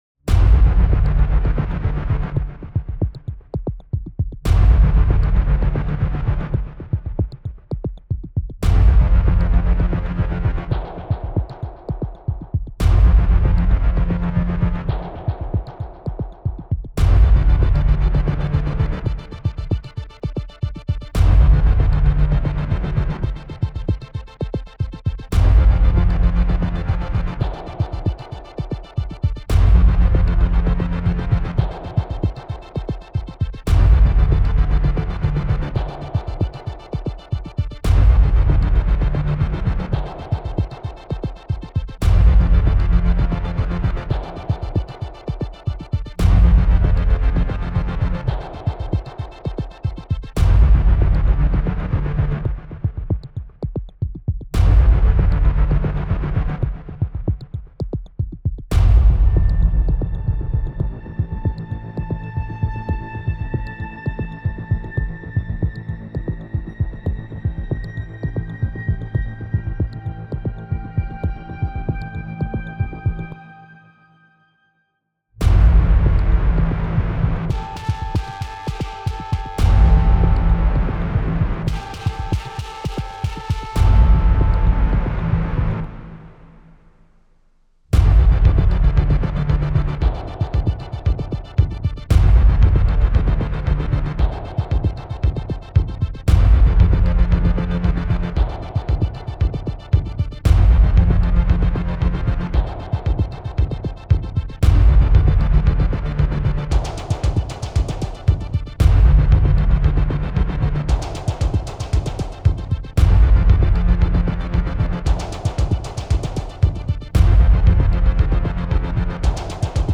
Horror, threat and sadness in menacing places